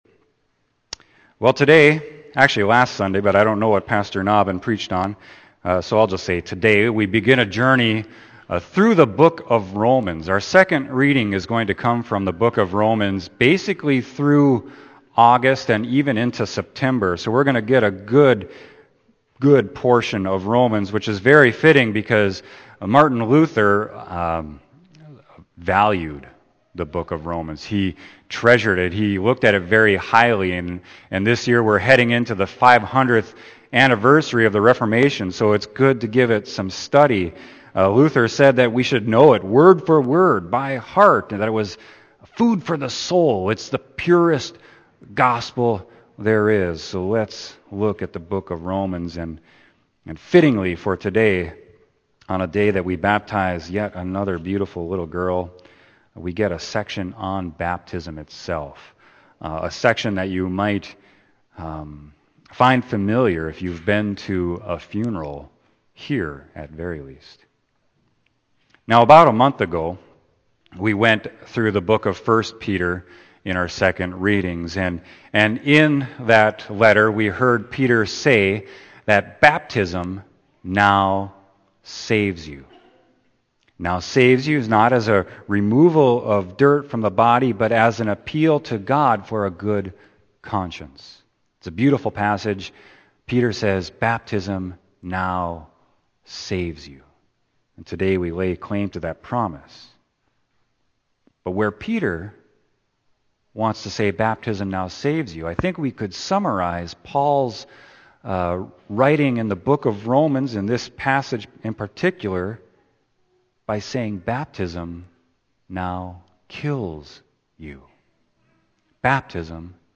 Sermon: Romans 6.1-11